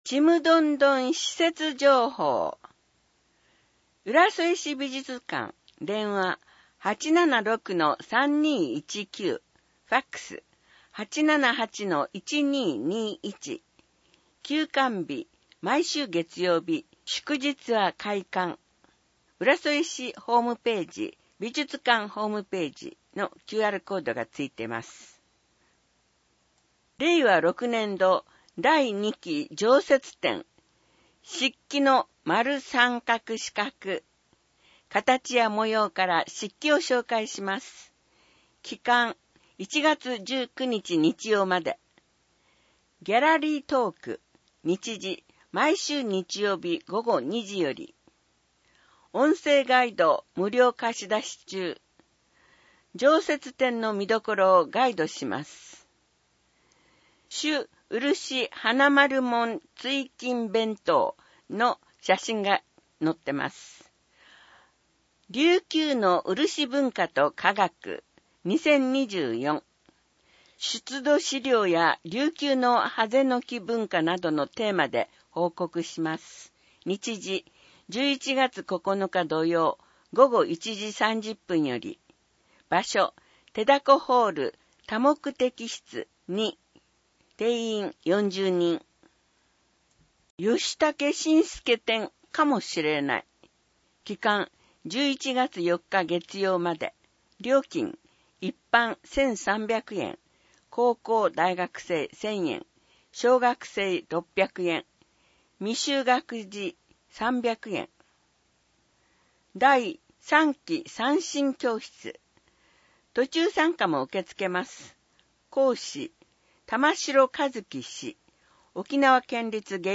声の広報 「広報うらそえ」を朗読したものを音声データ化しています。